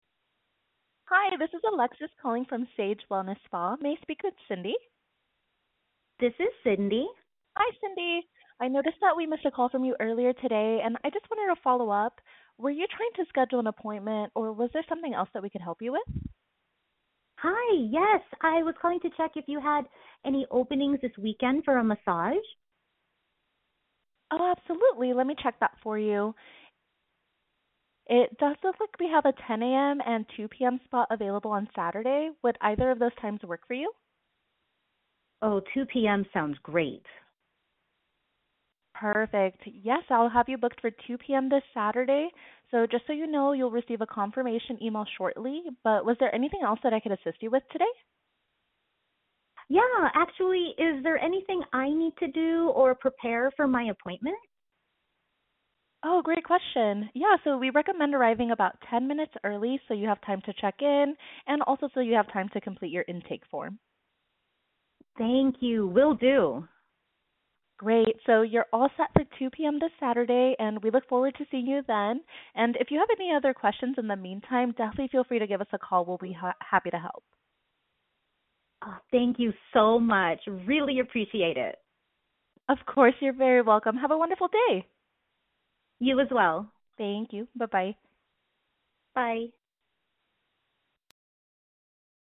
Missed Call Follow-Up
HUMAN RECEPTIONIST
Missed-Call-Follow-Up-Human.mp3